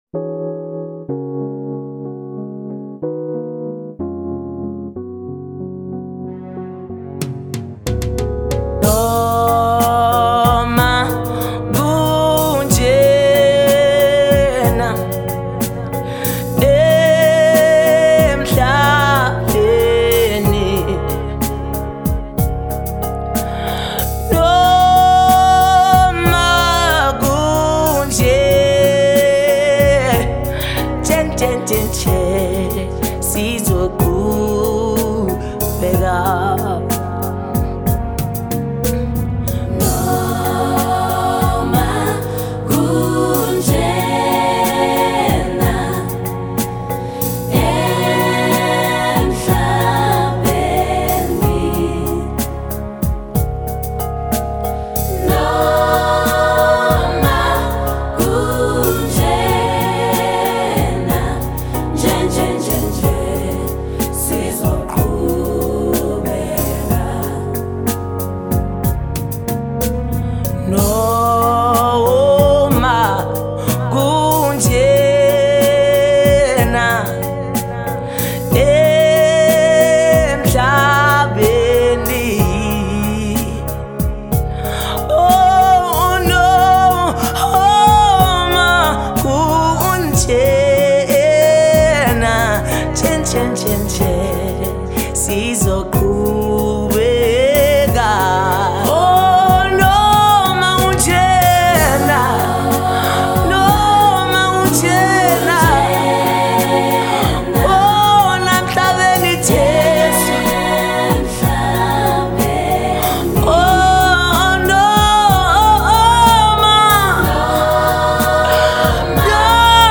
GENRE: South African Gospel.